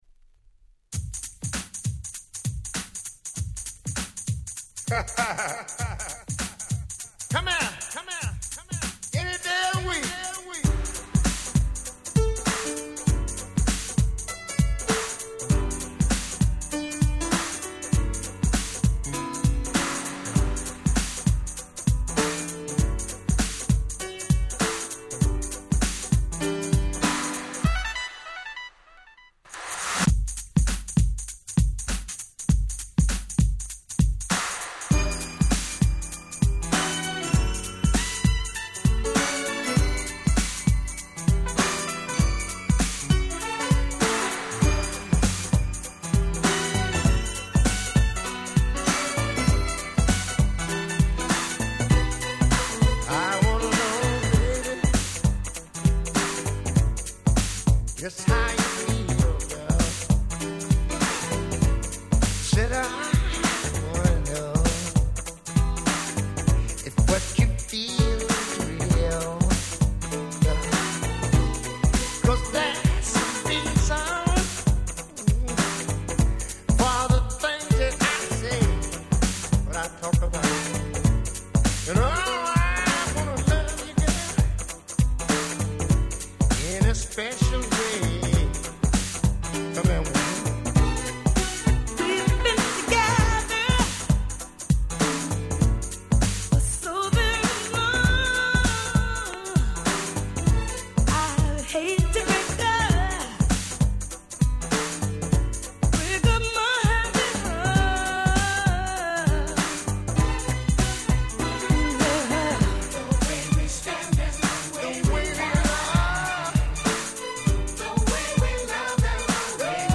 89' Super Hit R&B !!